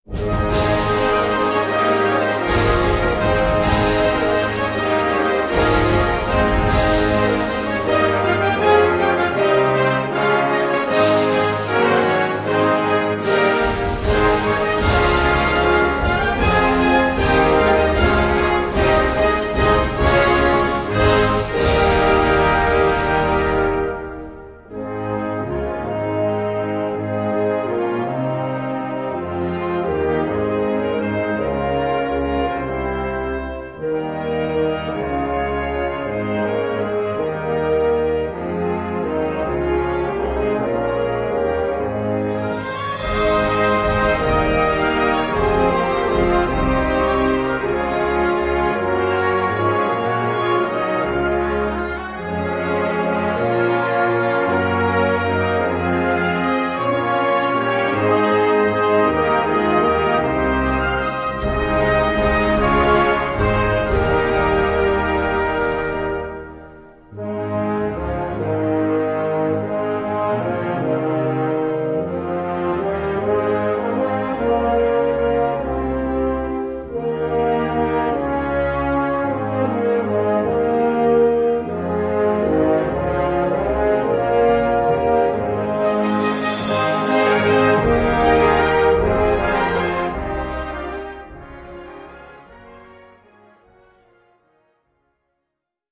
Gattung: Religiöse Blasmusik
Besetzung: Blasorchester